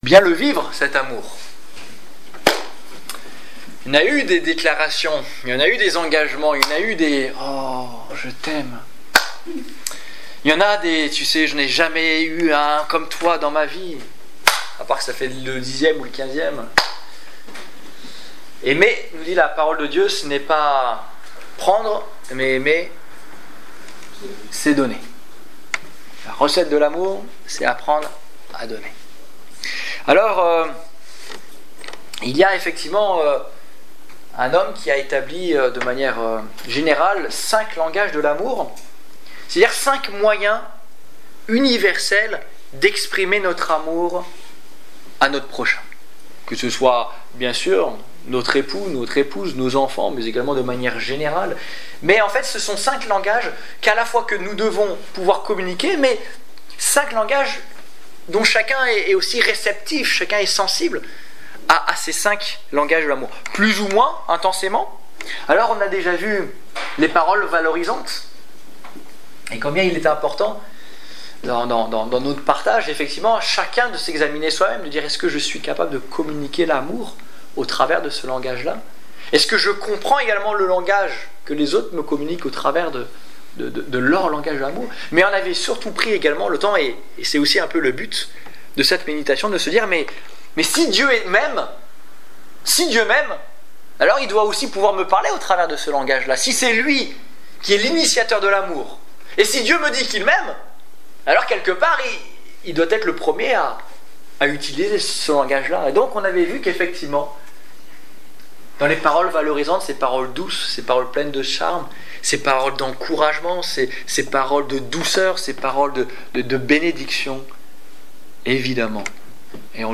Évangélisation du 27 février 2015